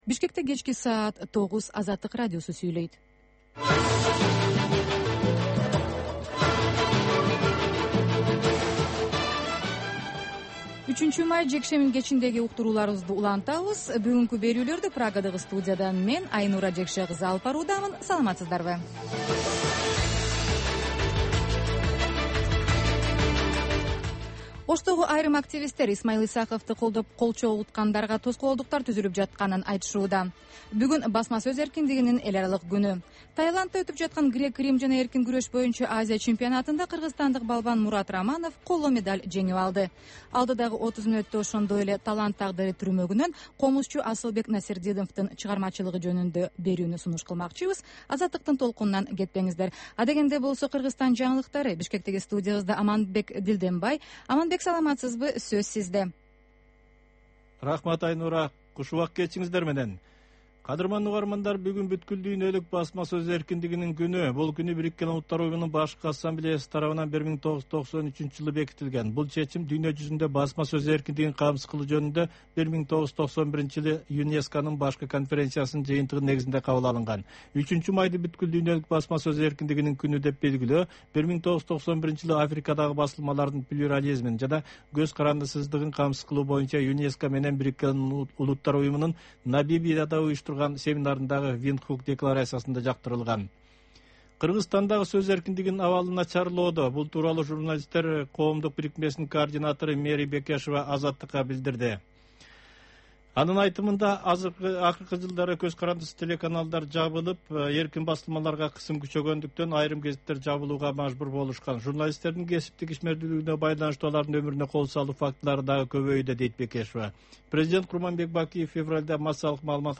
Бул кечки үналгы берүү жергиликтүү жана эл аралык кабарлардан, репортаж, маек, баян жана башка берүүлөрдөн турат. Ал ар күнү Бишкек убактысы боюнча саат 21:00ден 21:30га чейин обого түз чыгат.